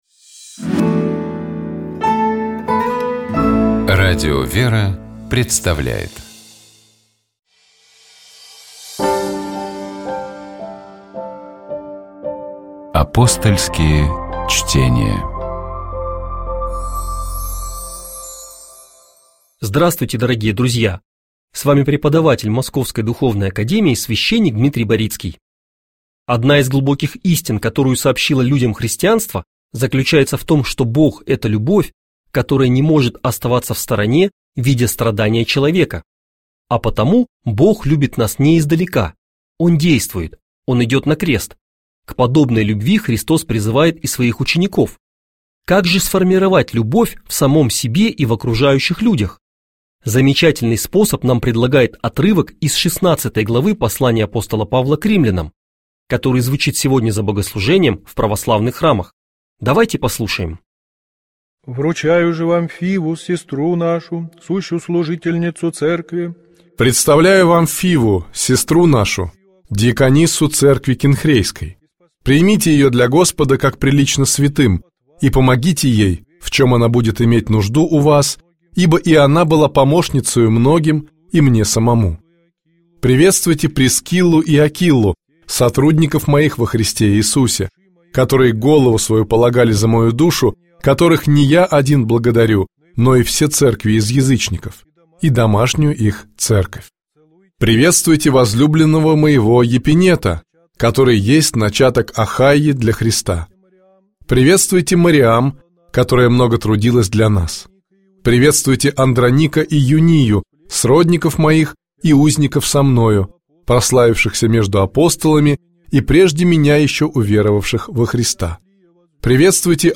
— Здравствуйте, дорогие слушатели, программа «Светлый вечер» на светлом радио.